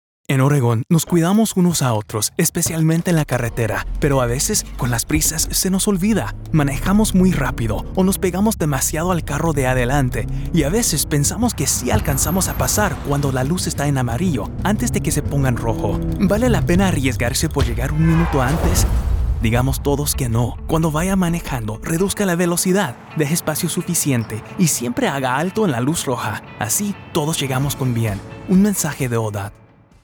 "Vale la pena" Radio PSA in Spanish - 30 seconds
SPEED_30s_Spanish_Radio_Vale_la_pena.mp3